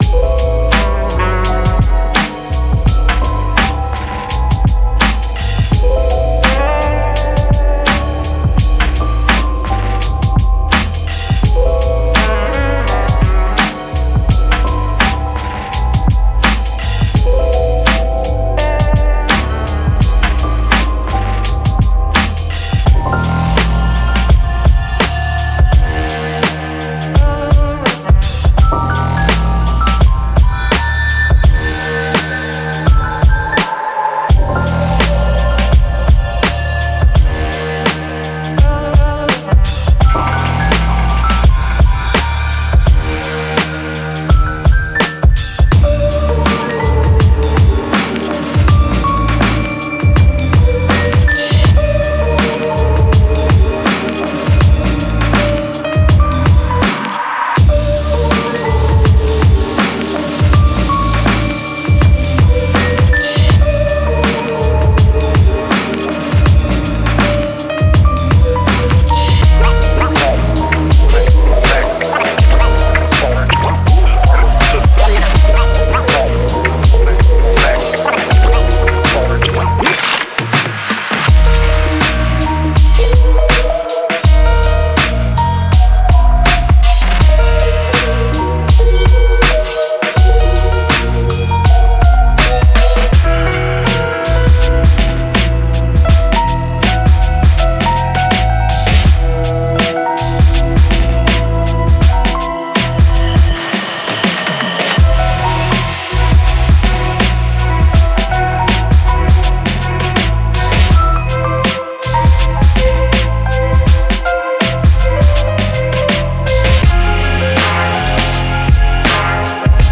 Genre:Hip Hop
デモサウンドはコチラ↓